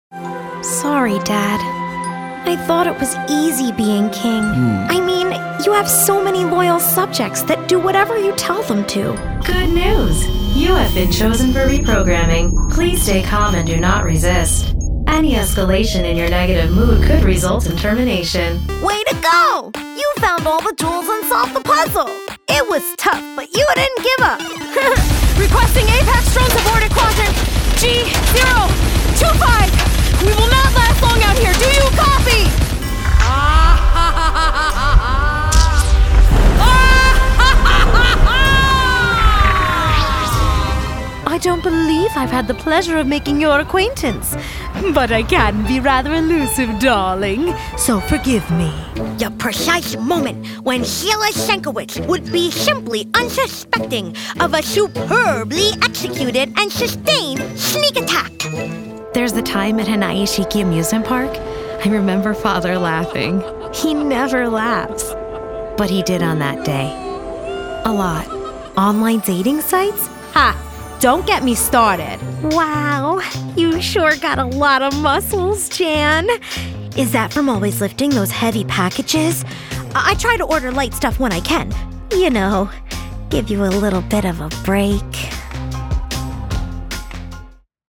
new york : voiceover : commercial : women